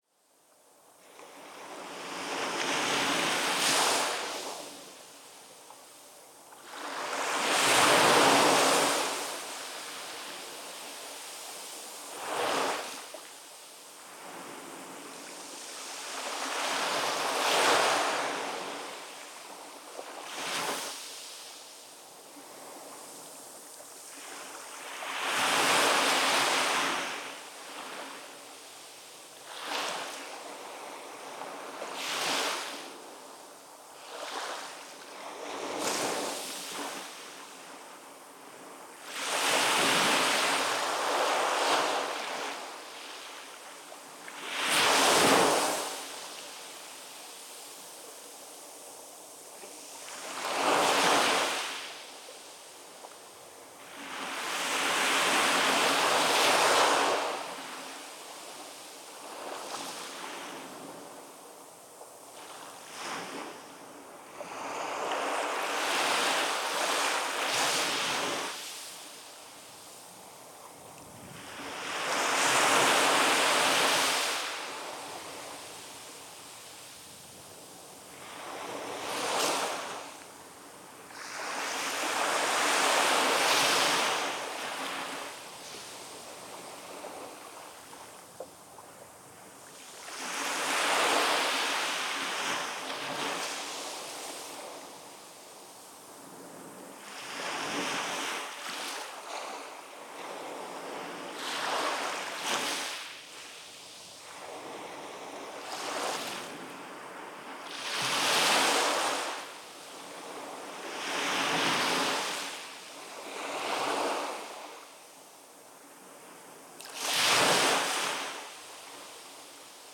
屋久島 - 栗生浜 10:16am(HPLバイノーラル)